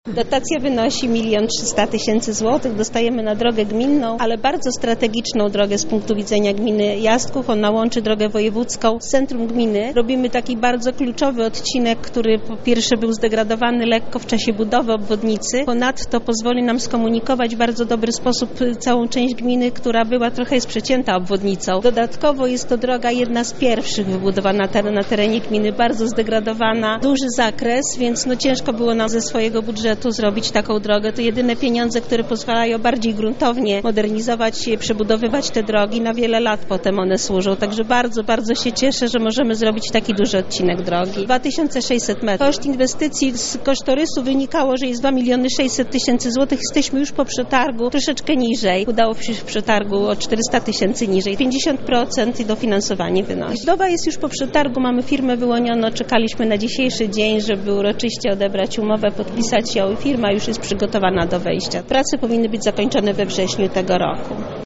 Otrzymaliśmy pieniądze na bardzo strategiczną drogę – mówi wójt gminy Jastków, Teresa Kot: